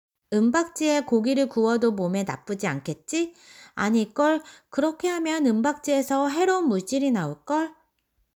Conversation Audio